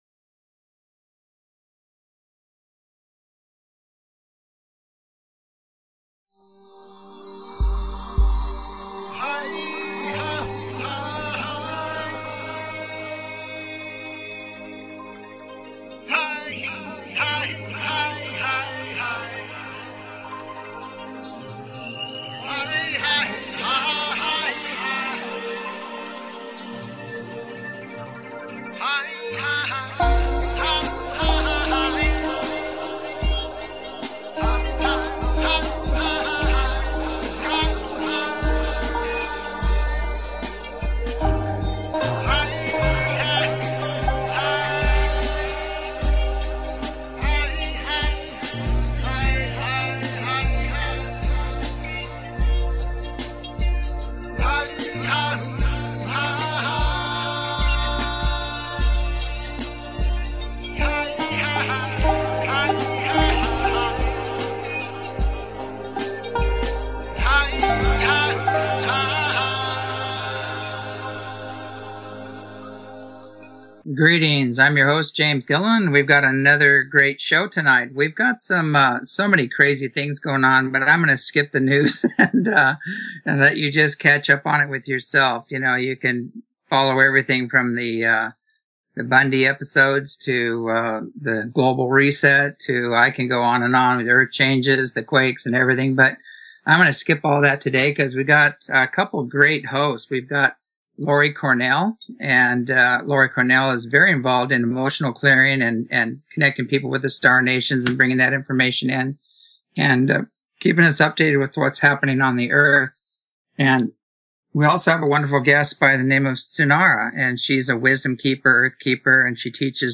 Talk Show Episode, Audio Podcast, As_You_Wish_Talk_Radio and Courtesy of BBS Radio on , show guests , about , categorized as
As you Wish Talk Radio, cutting edge authors, healers & scientists broadcasted Live from the ECETI ranch, an internationally known UFO & Paranormal hot spot.